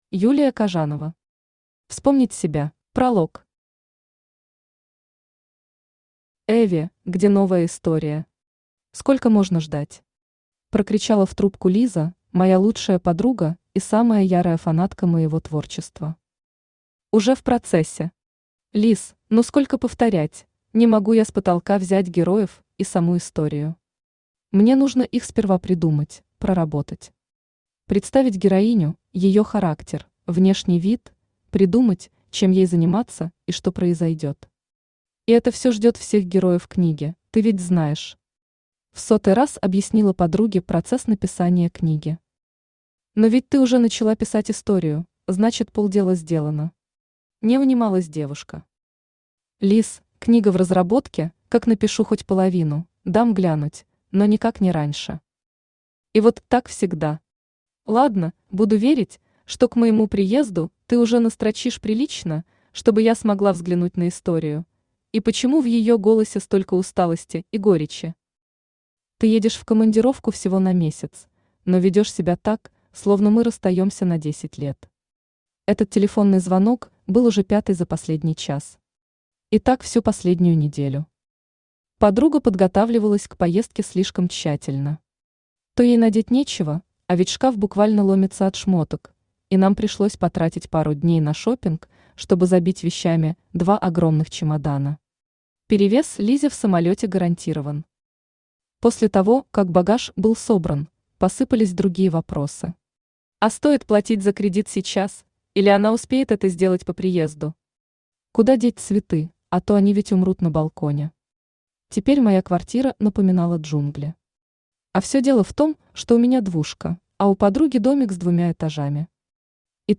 Читает: Авточтец ЛитРес
Аудиокнига «Вспомнить себя».